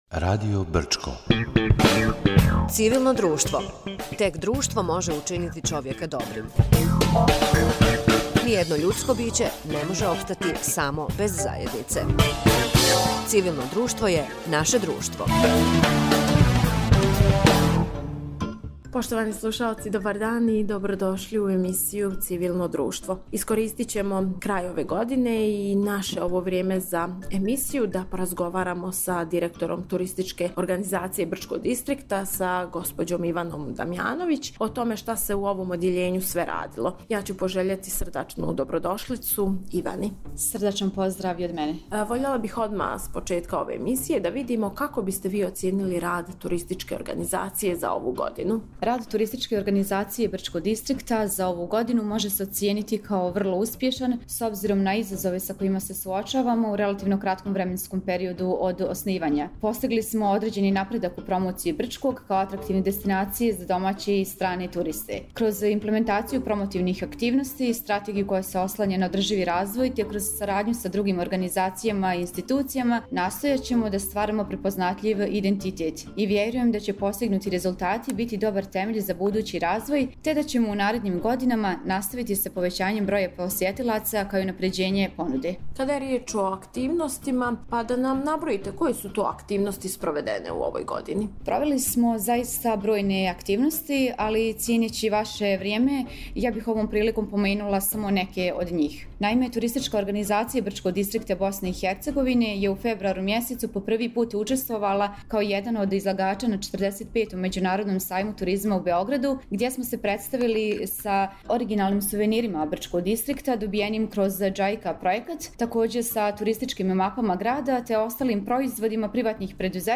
У емисији Цивилно друштво разговарали смо